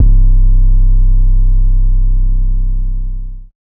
808s
{808} Massacurda.wav